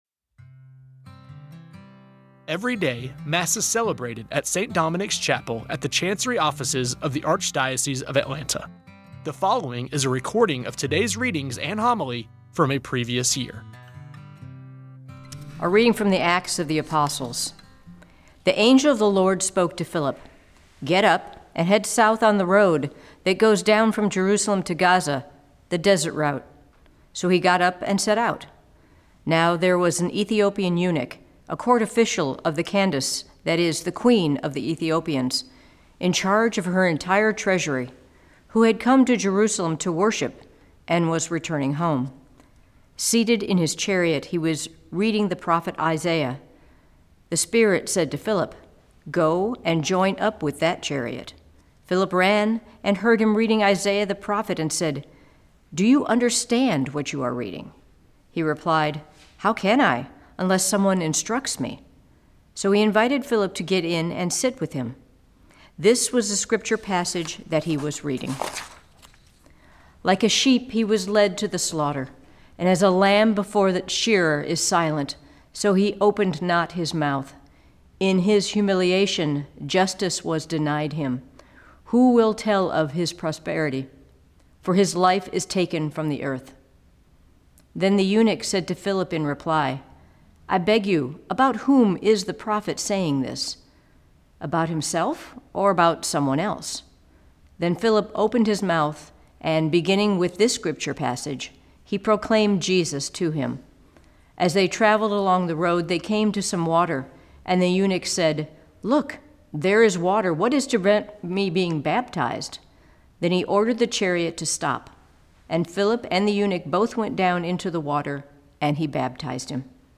Every day, Mass is celebrated at St. Dominic’s Chapel at the Chancery Offices of the Archdiocese of Atlanta. The following is a recording of today’s readings and homily from a previous year. You may recognize voices proclaiming the readings and homilies as employees, former employees, or friends of the Archdiocese.